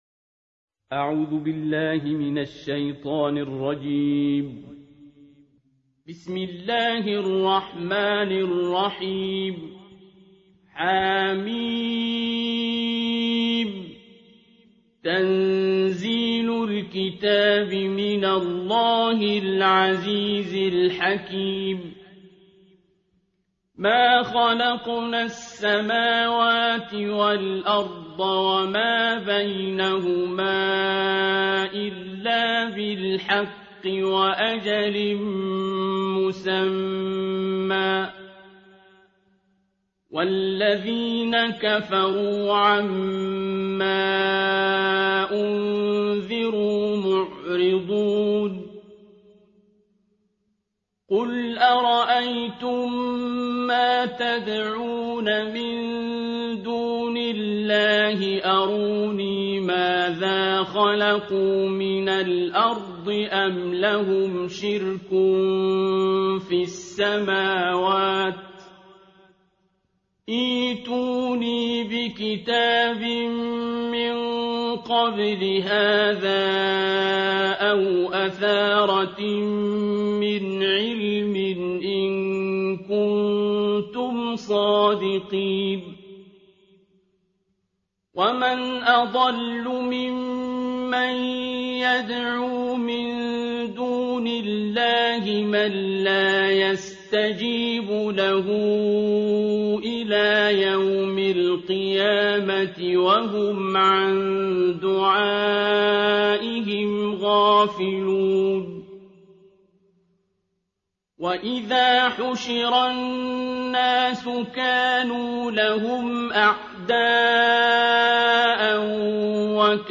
ترتیل جزء بیست و ششم قرآن کریم - استاد عبدالباسط
دانلود ترتیل جزء بیست و ششم قرآن کریم با صدای استاد عبدالباسط محمد عبدالصمد
در این بخش از ضیاءالصالحین، ترتیل جزء بیست و ششم قرآن کریم را با قرائت دلنشین استاد عبدالصمد عبدالباسط با علاقه مندان به قرآن کریم به اشتراک می گذاریم.